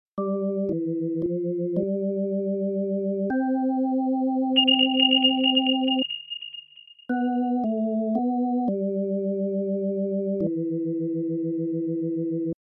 В качестве спутника звучит японский виброплекс B)